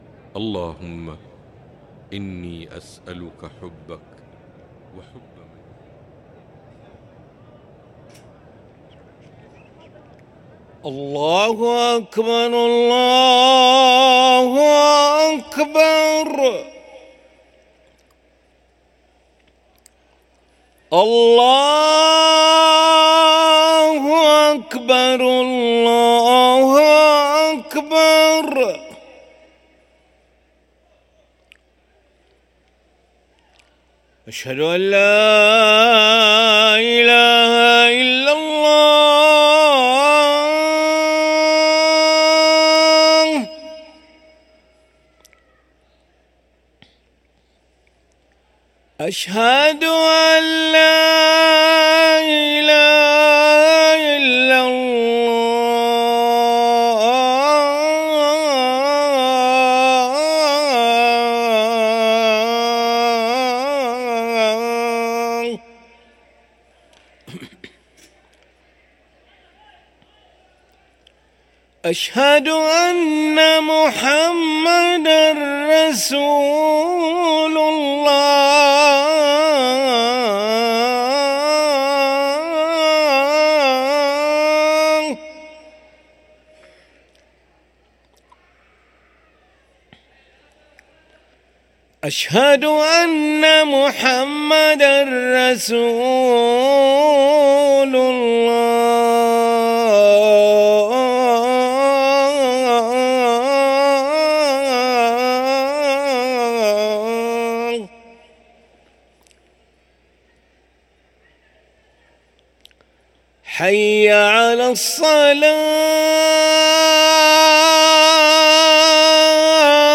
أذان العشاء للمؤذن علي ملا الأحد 5 جمادى الأولى 1445هـ > ١٤٤٥ 🕋 > ركن الأذان 🕋 > المزيد - تلاوات الحرمين